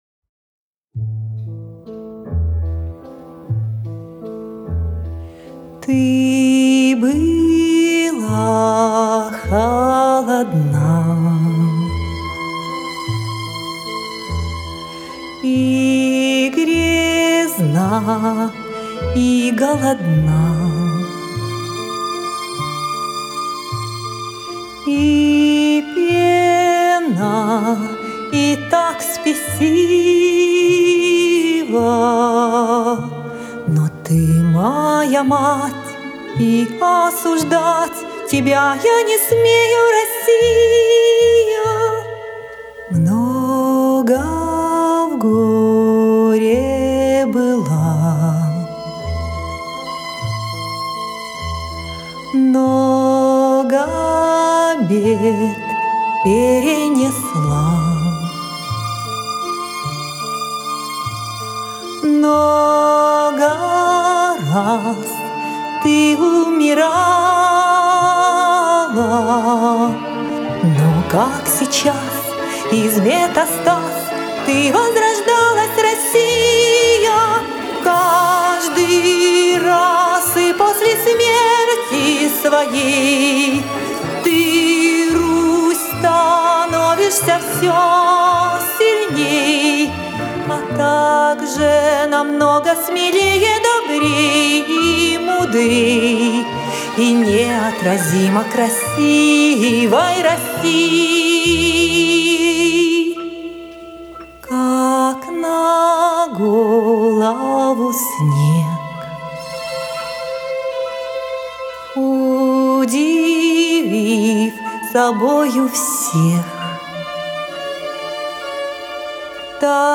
На мелодию вальса